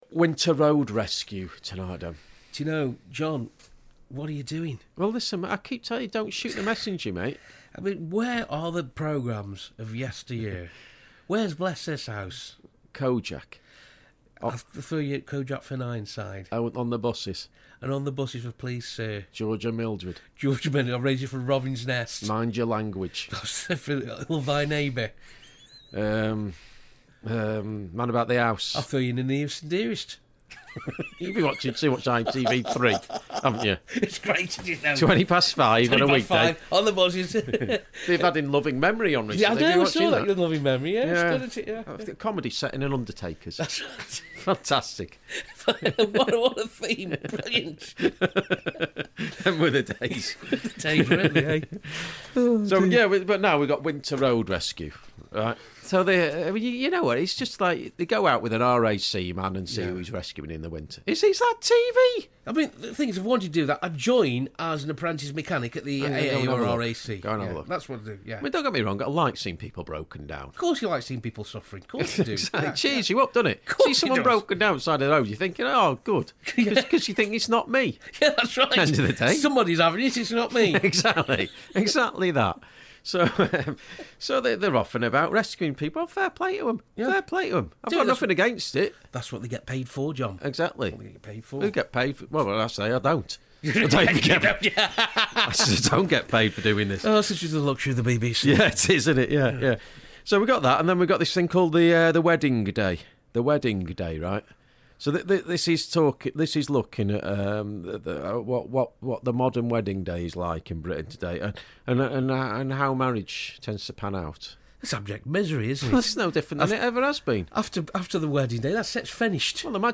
Meaningless drivel from menopausal men